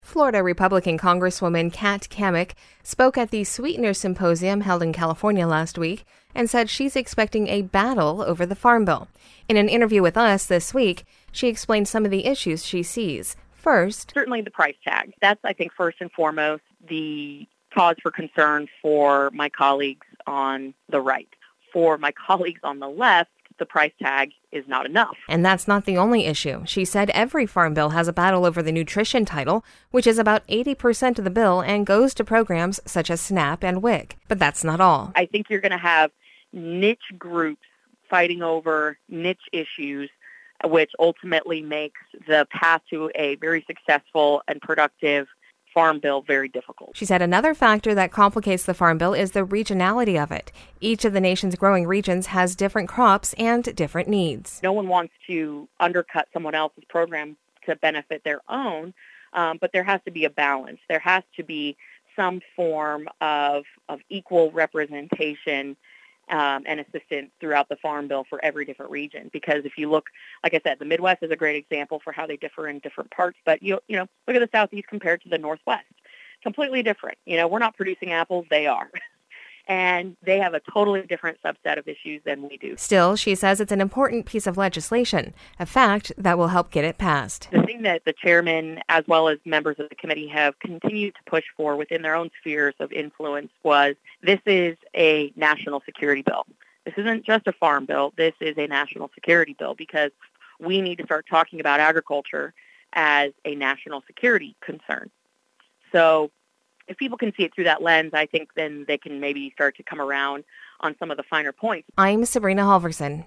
In an interview with us on Monday, she explained some of the issues she expects.